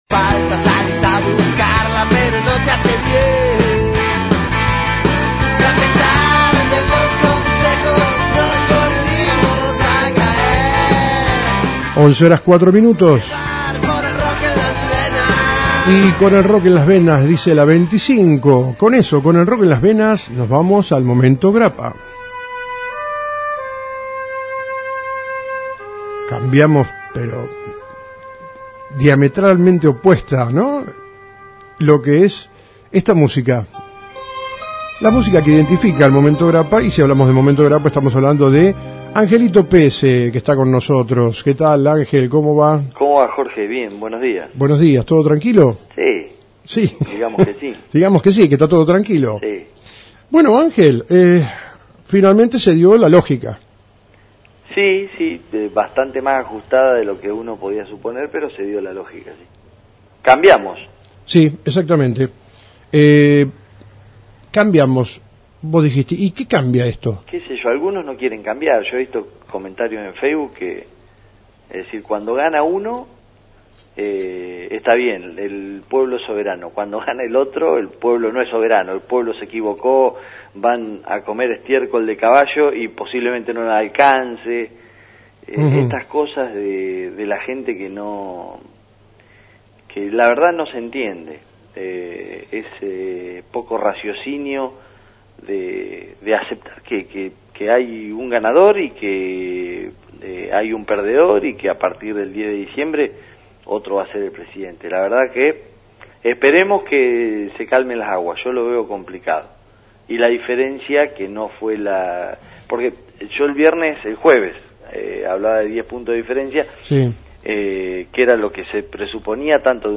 Periodistas